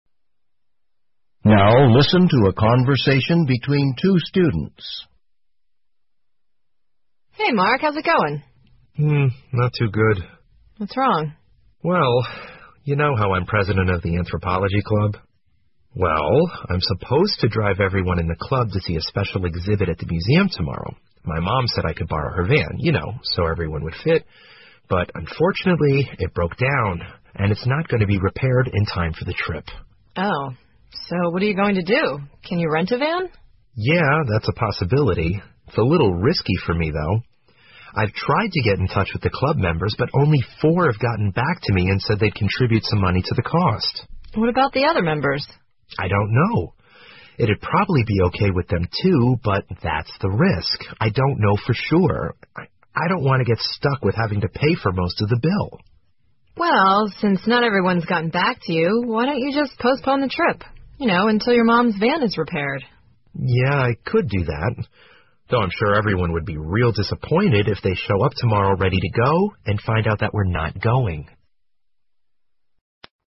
托福口语练习 两个学生的对话 听力文件下载—在线英语听力室